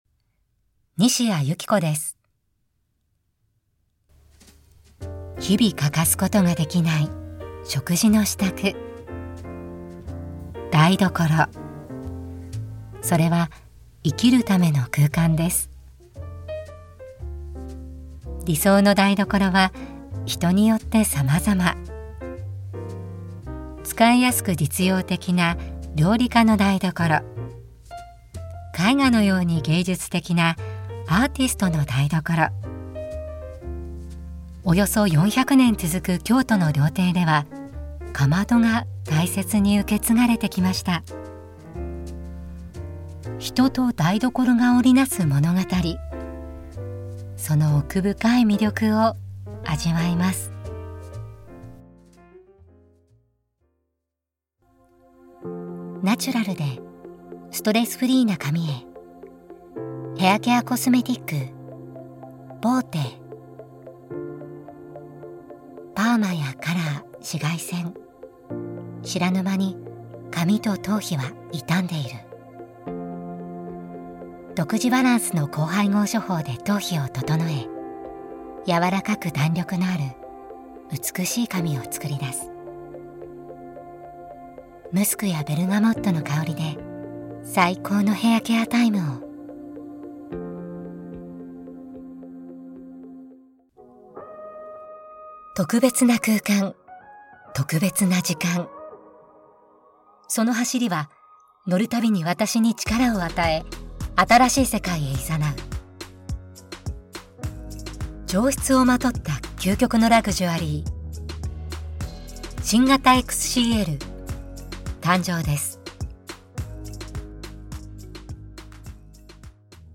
ソフトで落ち着いた明るい声